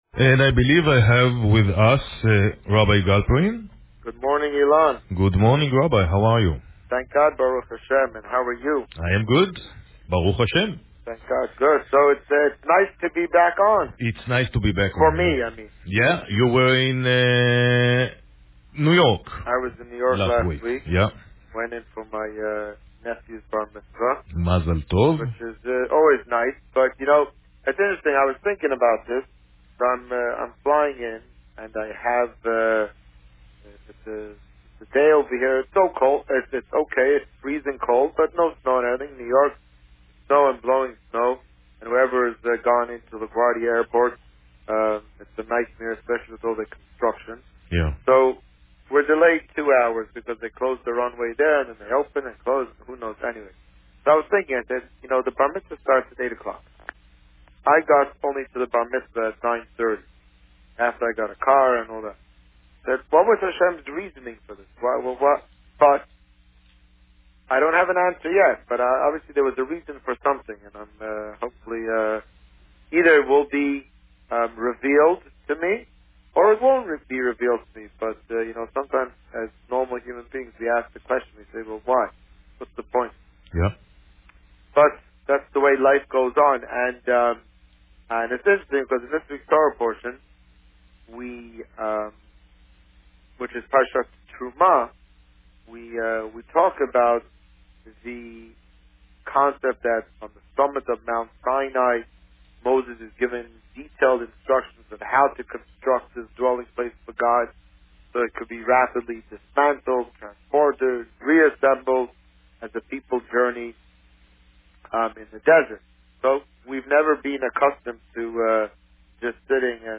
The Rabbi on Radio